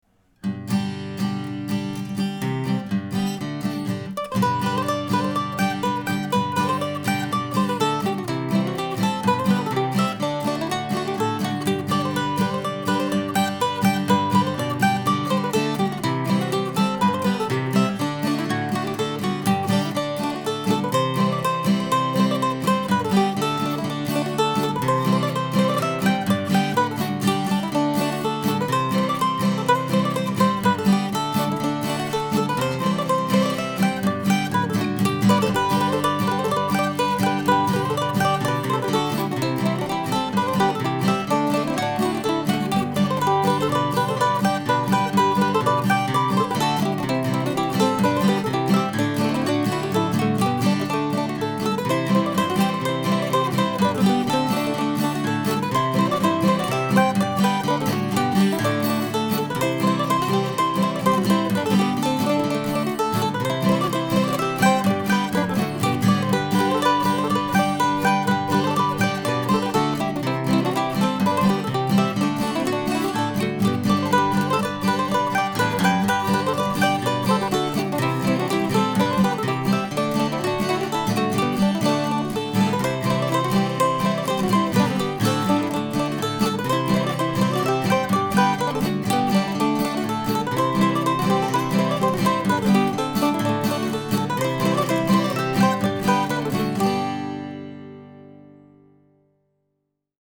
I wrote most of today's new tune, meant to be a polka, last Sunday morning but I continued to change a note or two all through the week. I recorded on Friday but I had to keep making the harmony part less and less busy because I just couldn't play my original notes.